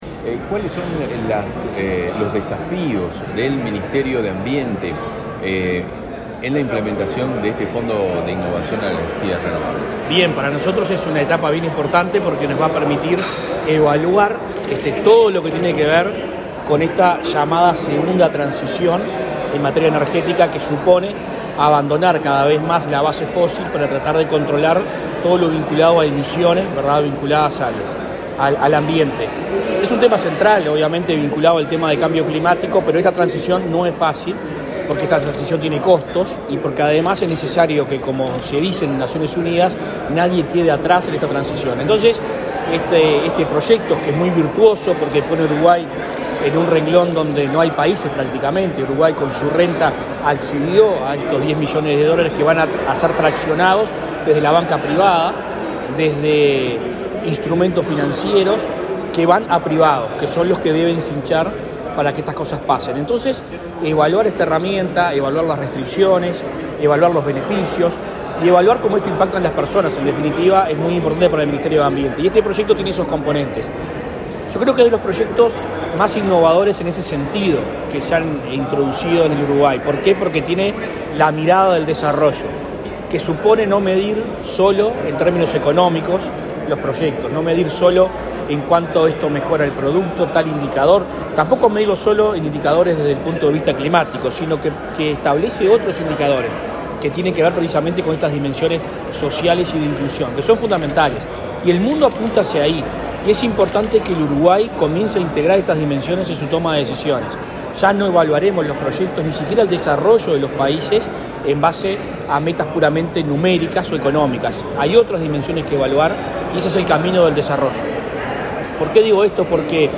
Declaraciones a la prensa del ministro de Ambiente. Adrián Peña
Adrián Peña 17/05/2022 Compartir Facebook X Copiar enlace WhatsApp LinkedIn El ministro de Ambiente participó, este 17 de mayo, en el lanzamiento del Fondo de Innovación en Energías Renovables de Uruguay (REIF), para impulsar la segunda transición energética de Uruguay. Tras el evento, efectuó declaraciones a la prensa.